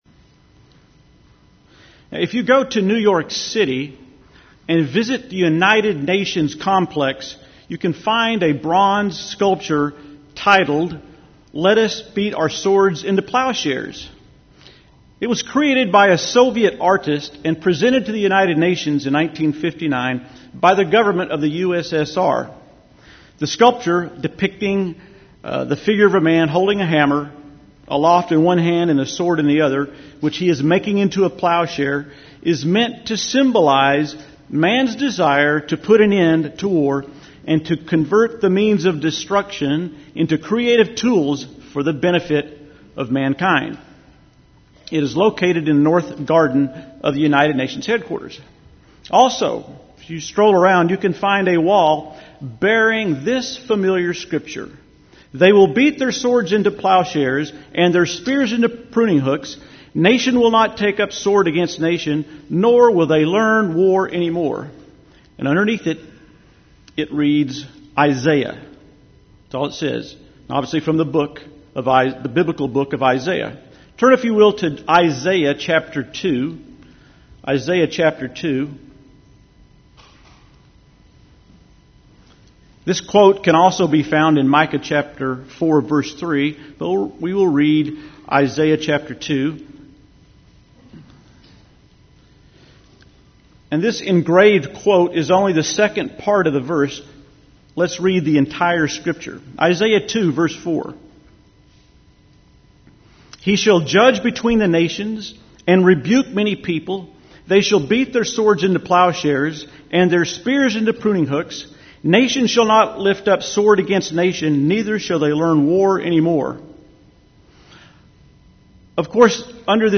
This sermon was given at the New Braunfels, Texas 2012 Feast site.